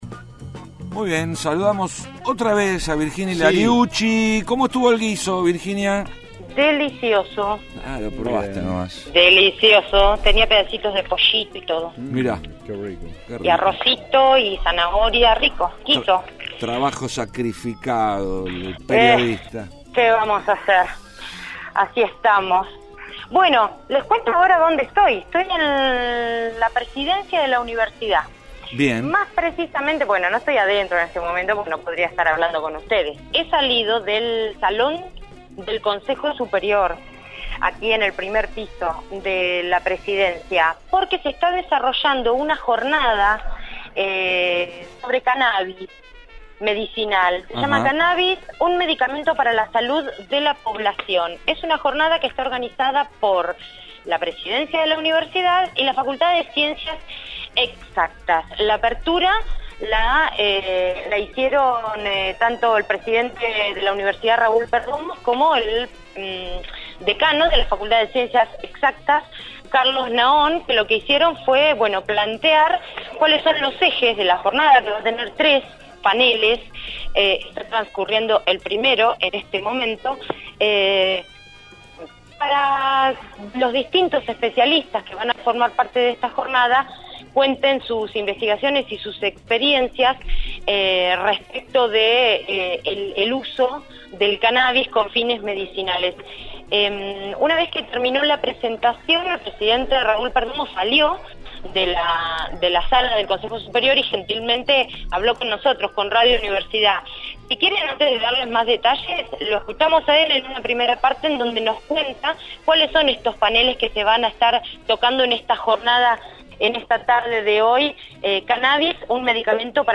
Organizada por la Presidencia de la Universidad Nacional de La Plata y la Facultad de Ciencias Exactas, la Jornada estuvo destinada a investigadores de Facultades, Institutos de Investigación, autoridades nacionales, provinciales, y legisladores. Escuchamos la palabra del Presidente de la UNLP, Licenciado Raúl Perdomo.